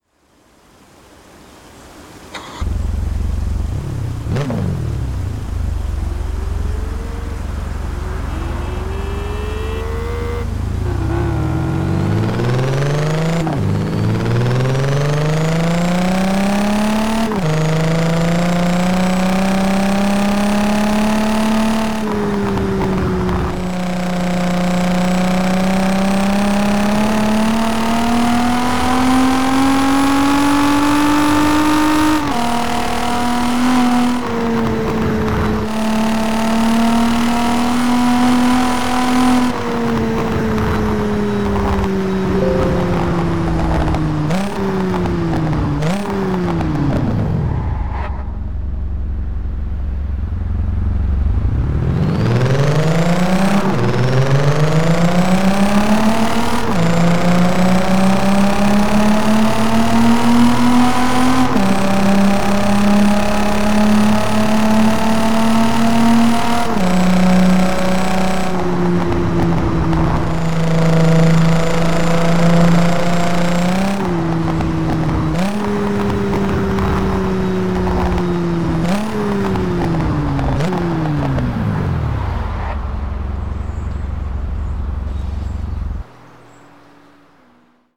- Audi S4 [B5] [SC / Backfire]